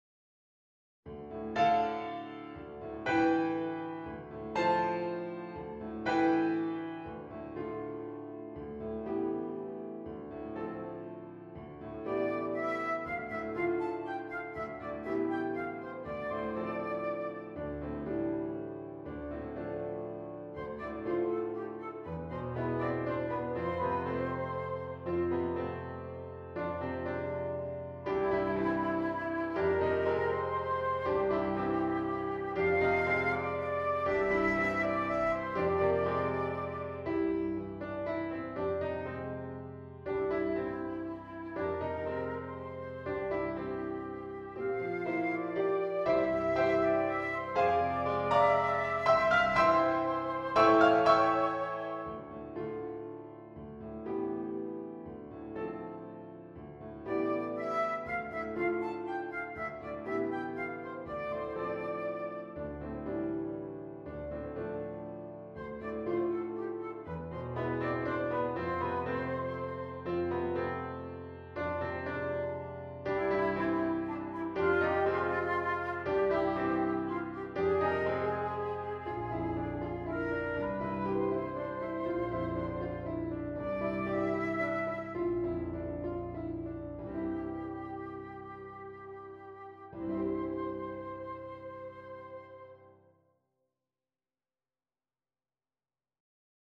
short solos
Flute version
• Reference mp3 Flute version